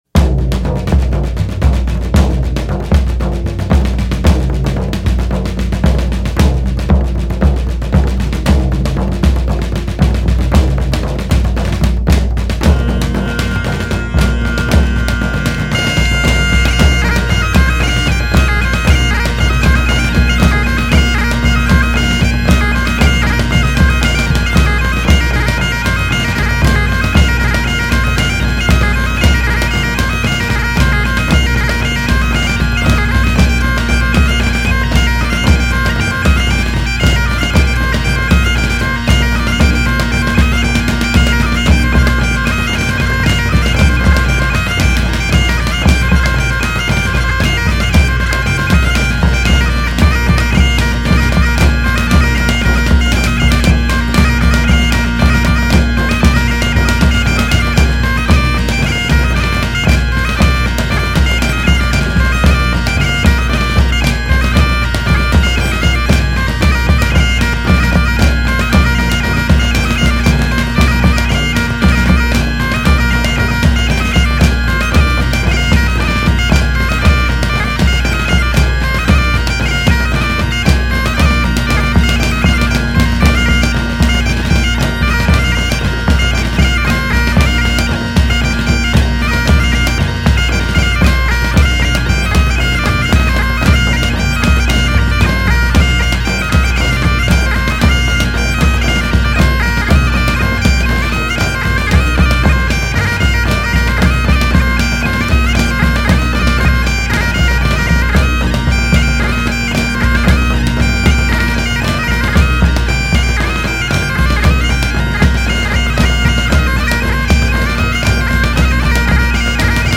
这支乐团，融合了高地风笛的热情和原始部落的韵律，以诙谐的风格再现了一百多年前苏格兰婚礼、同乐会或高地狩猎营火会的音乐。